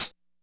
HI HAT III.wav